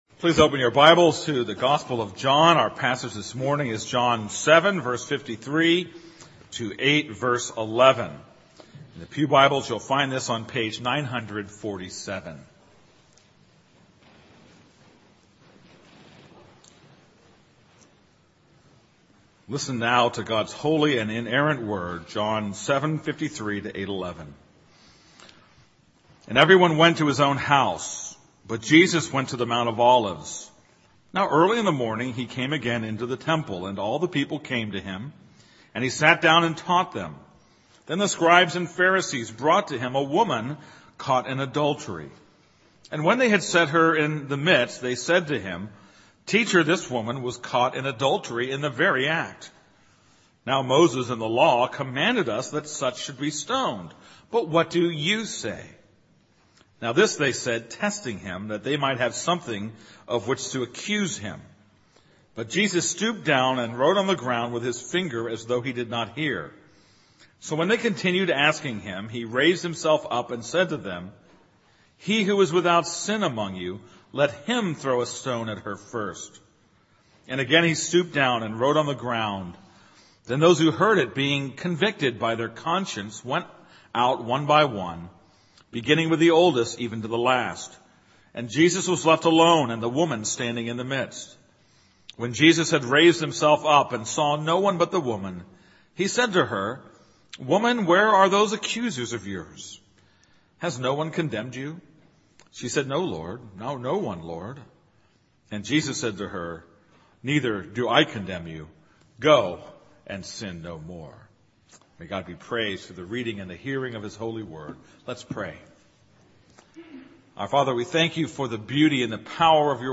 This is a sermon on John 7:53-8:11.